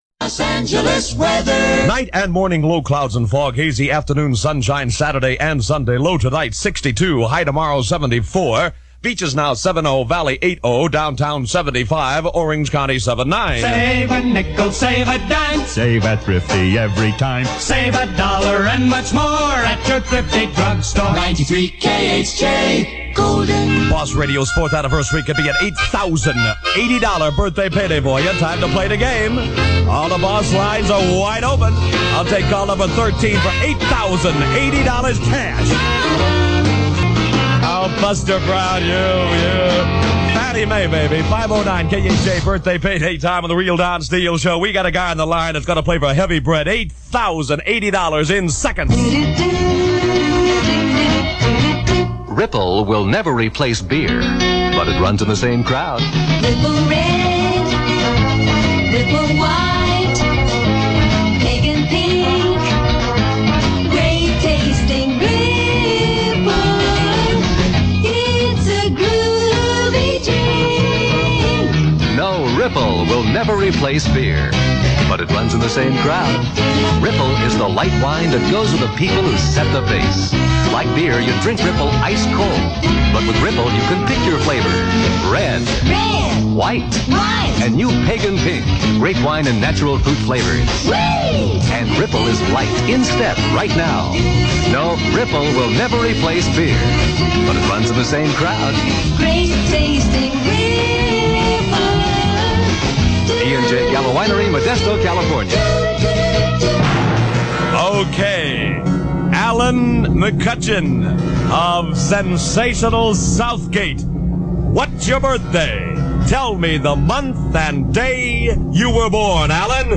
That sense of humour was part of a memorable act that carried Steele through eight years as the afternoon drive jock on KHJ .
Enjoy The Real Don Steele on KHJ (SCOPED) here .